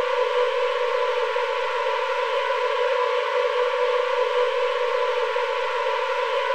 ambiance__small_tunnel.wav